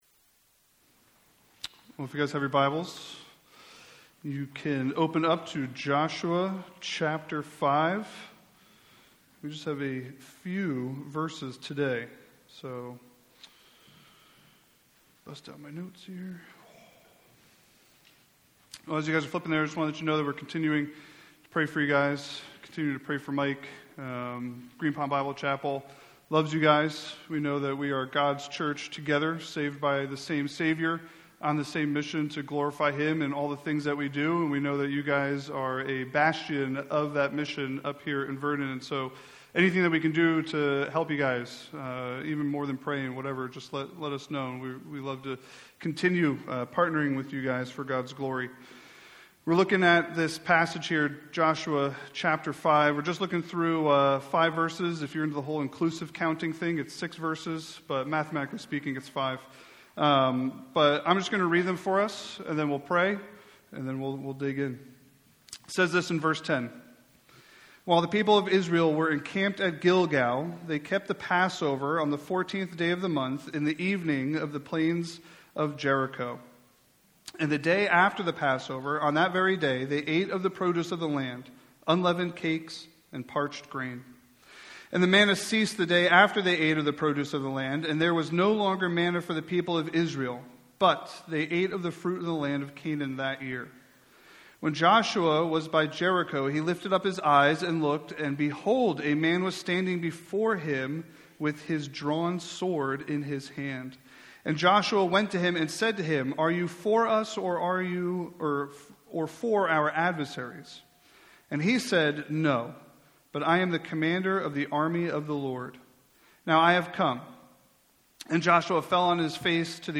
A message from the series "Joshua."